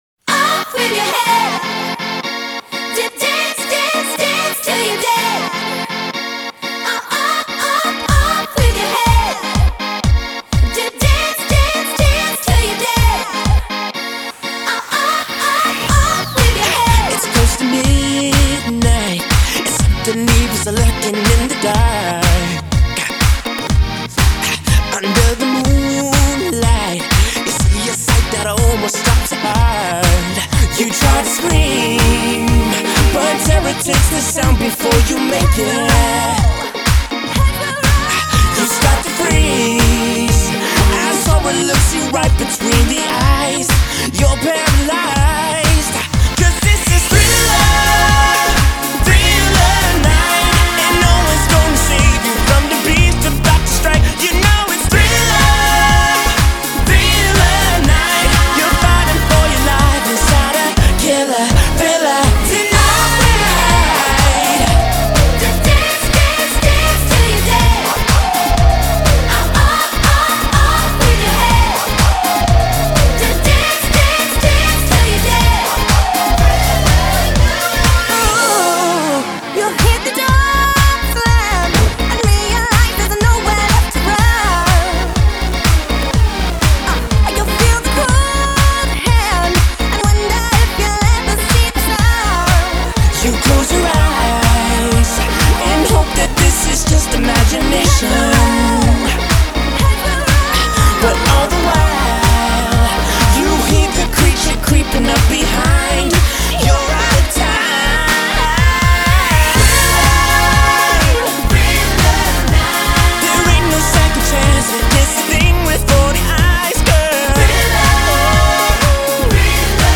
BPM123-123
Audio QualityPerfect (High Quality)
Pop/Mashup song for StepMania, ITGmania, Project Outfox
Full Length Song (not arcade length cut)